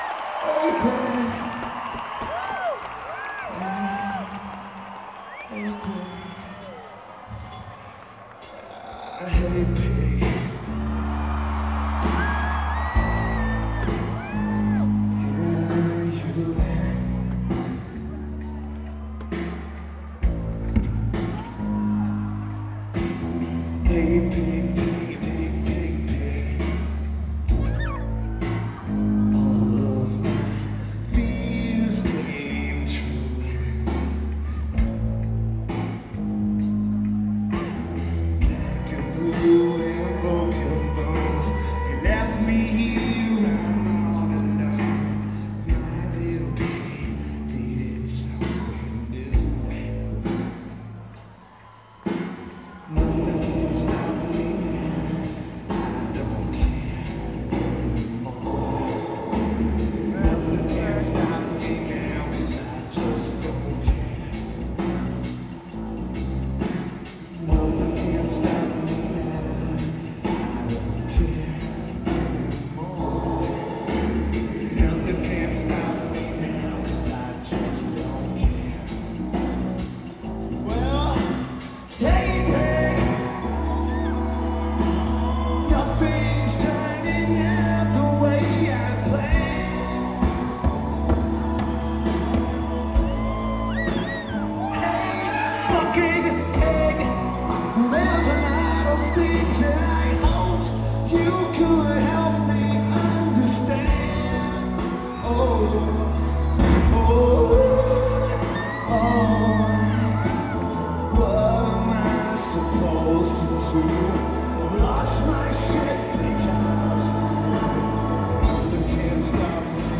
Worcester, MA United States
Keyboards/Programming
Bass/Guitar
Drums
Vocals/Guitar/Keyboards
Lineage: Audio - AUD (CSBs + Sony TCD-D8 *LP Mode*)